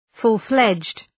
Shkrimi fonetik {,fʋl’fledʒd}